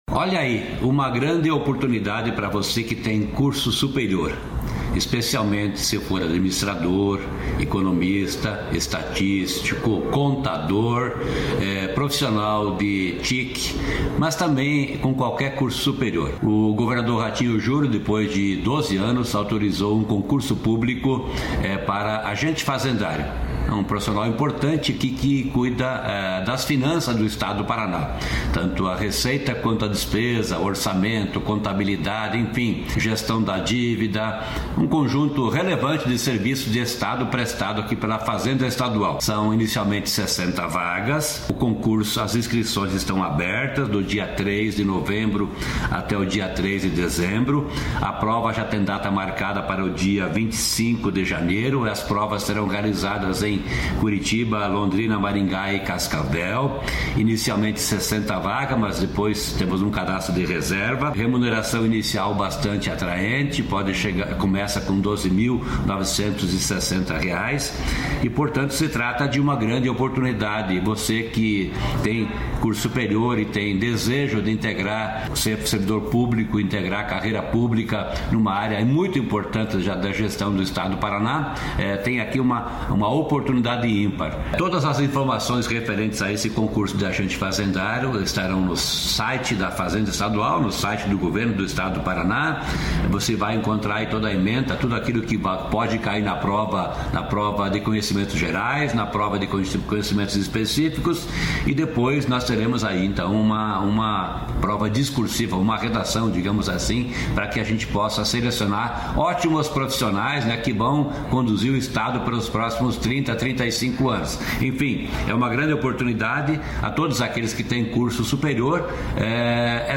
Sonora do secretário estadual da Fazenda, Norberto Ortigara, sobre o concurso para agente fazendário